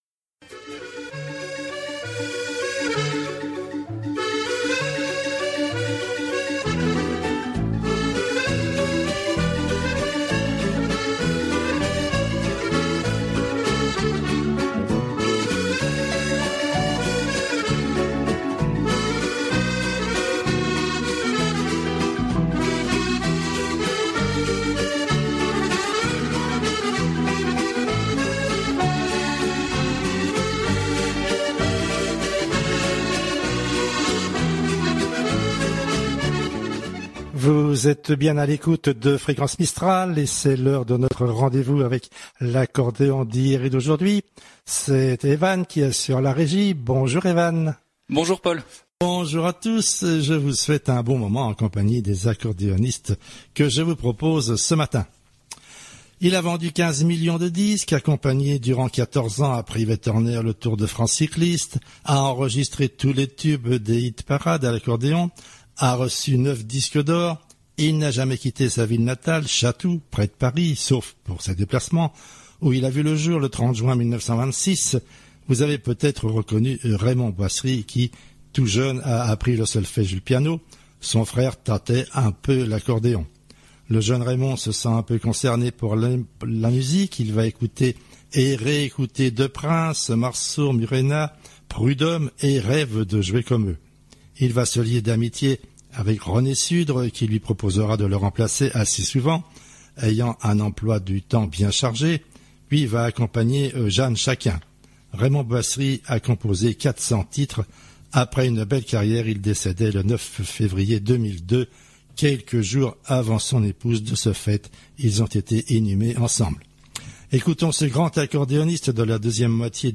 Accordéon